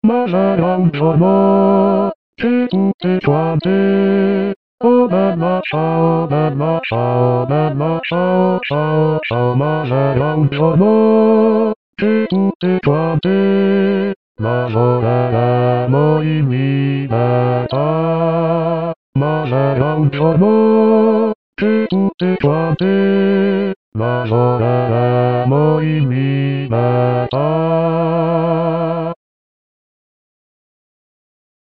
Mp3 di studio
bassi